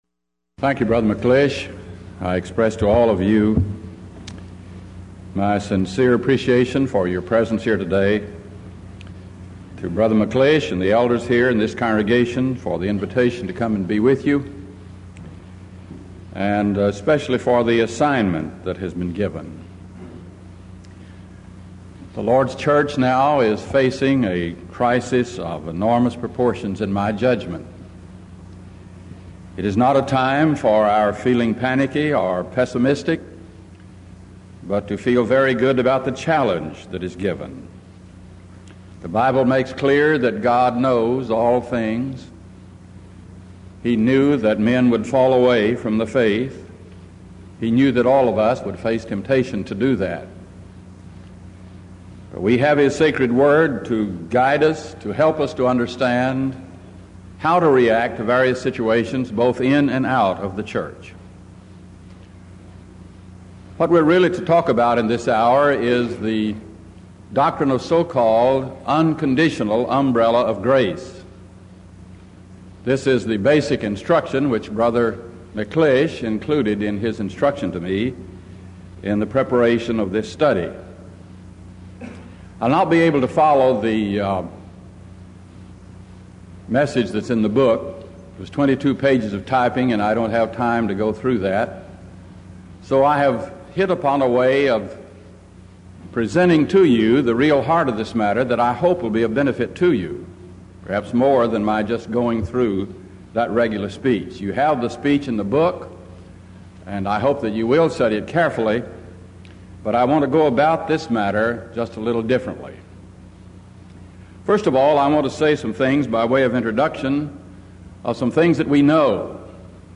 Event: 1989 Denton Lectures
lecture